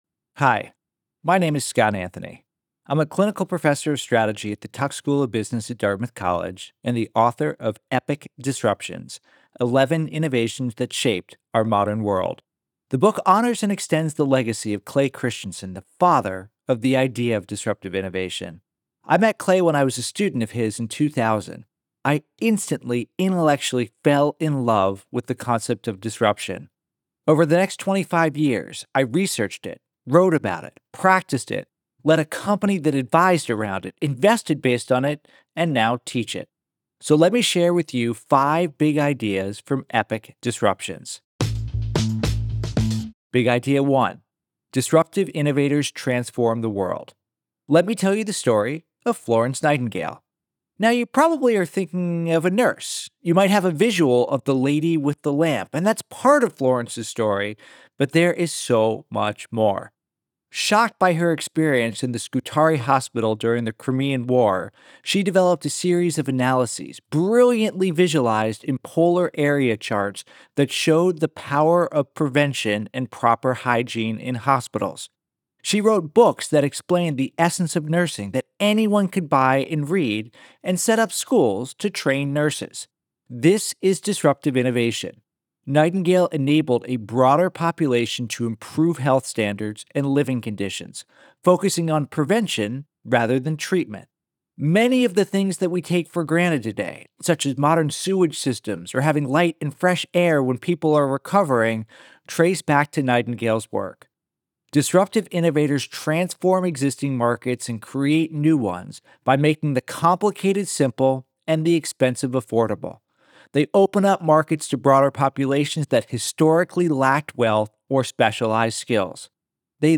How do we make sense of a world where change is truly the only constant? Understanding how disruptive innovation and epic change happens allows us to see the world more clearly. Listen to the audio version of this Book Bite